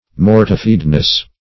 Search Result for " mortifiedness" : The Collaborative International Dictionary of English v.0.48: Mortifiedness \Mor"ti*fied*ness\, n. The state of being mortified; humiliation; subjection of the passions.
mortifiedness.mp3